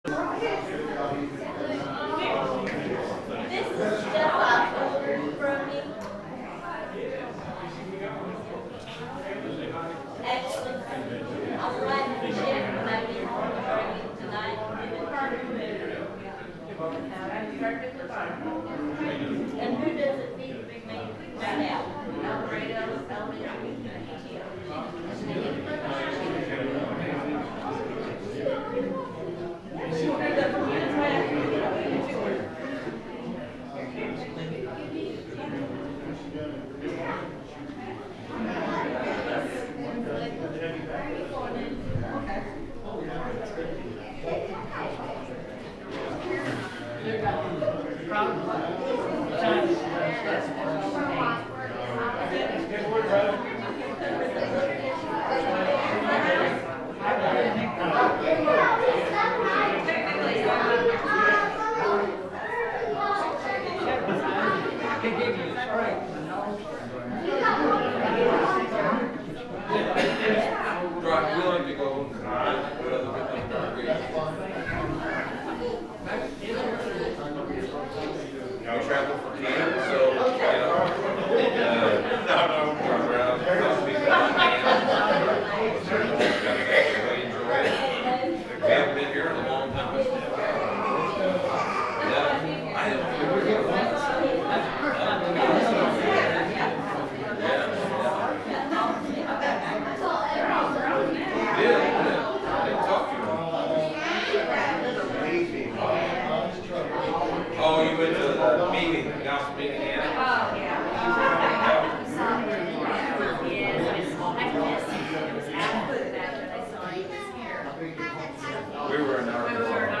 The sermon is from our live stream on 10/19/2025